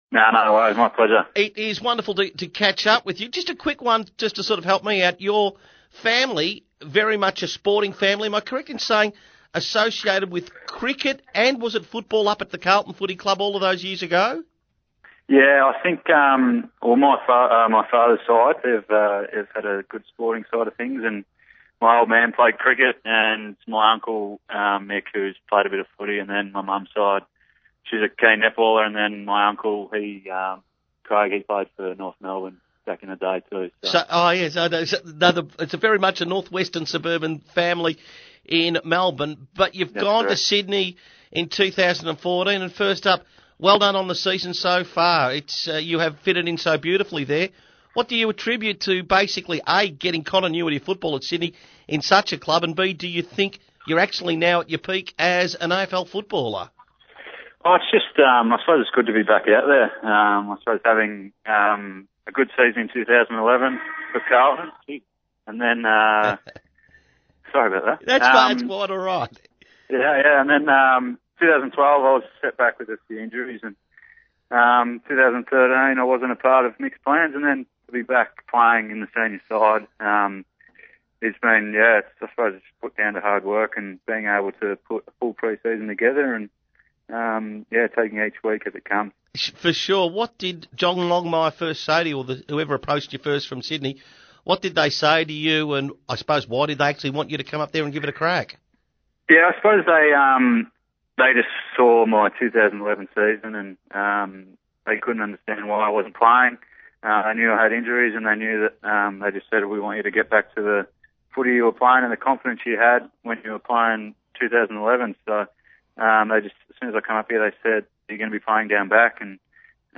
Sydney Swans defender Jeremy Laidler appeared on Crocmedia's Sportsday program on Thursday June 5, 2014.